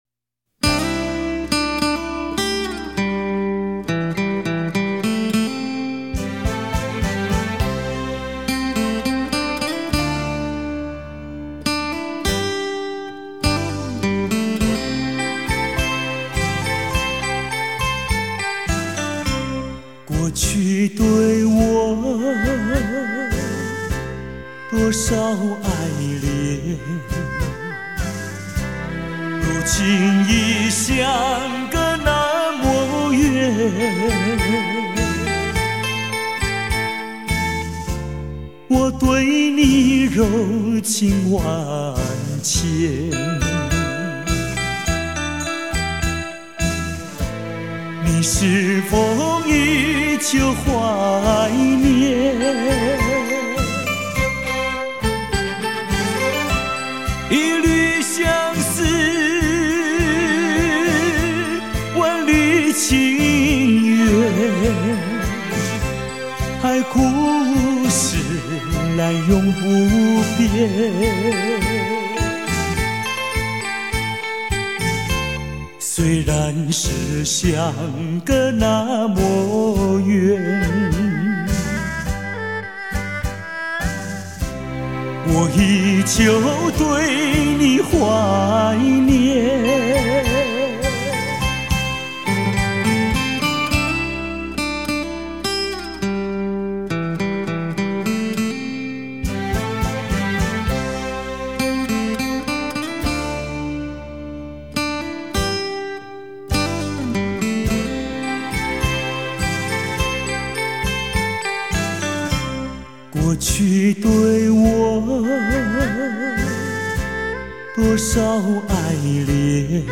音质非常ok的